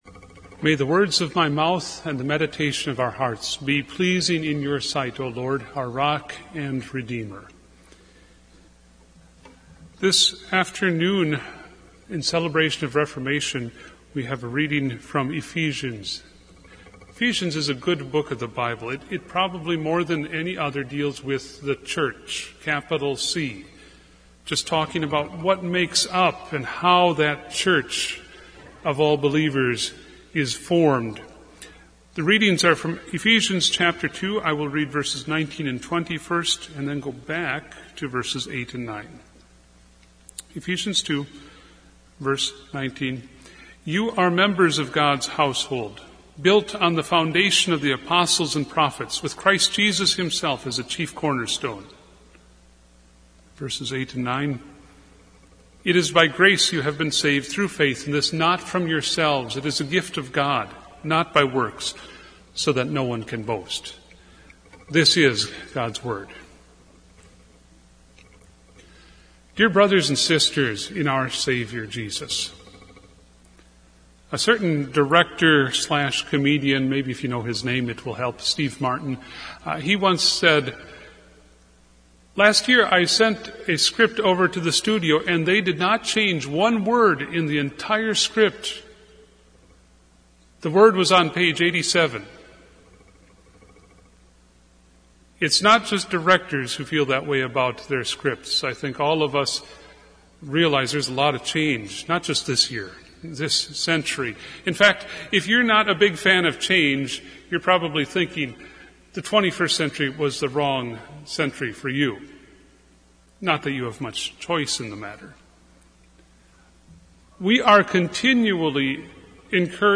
Lutheran Sermons- Audio & Video Archives
Media Replay of Lutheran Reformation Service